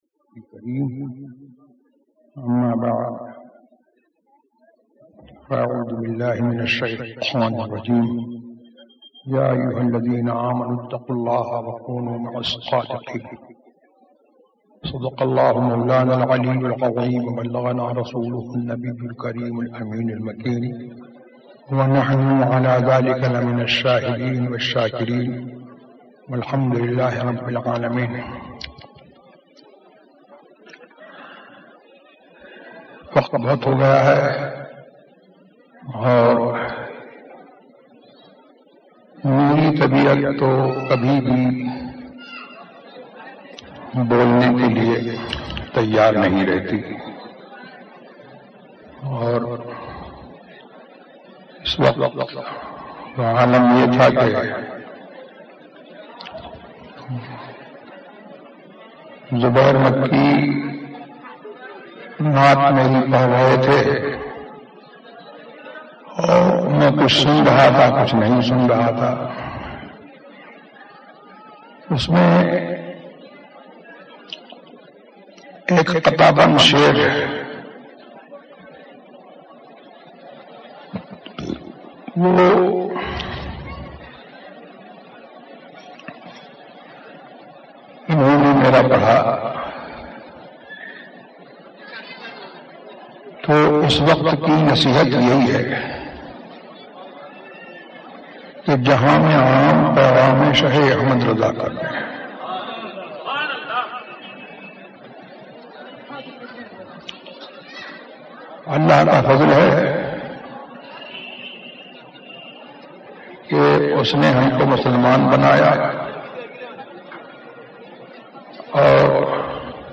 دین اور رشتہ داری ZiaeTaiba Audio میڈیا کی معلومات نام دین اور رشتہ داری موضوع تقاریر آواز تاج الشریعہ مفتی اختر رضا خان ازہری زبان اُردو کل نتائج 921 قسم آڈیو ڈاؤن لوڈ MP 3 ڈاؤن لوڈ MP 4 متعلقہ تجویزوآراء